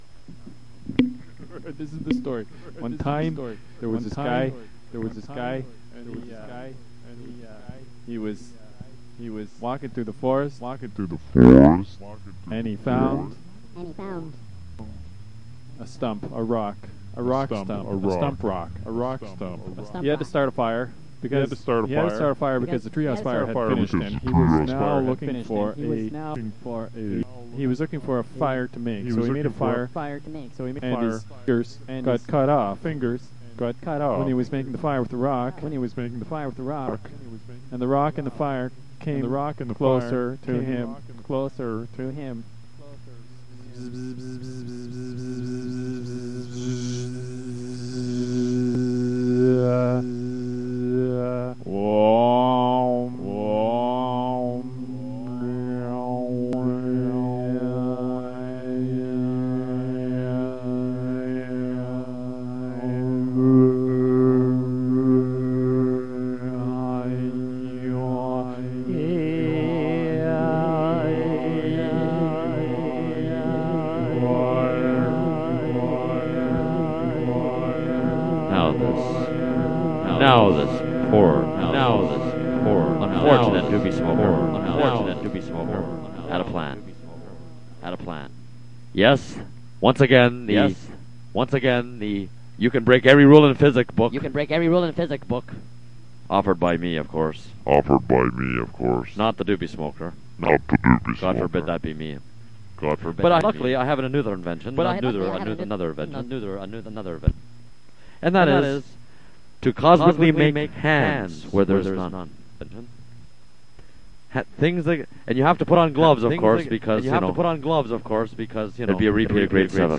描述：延迟主义者提出了更多的口语废话。
Tag: VOX 重复 乱码 踏板 流水声 官样文章 和谐 延迟 delayist 声乐 无义 口语 讲故事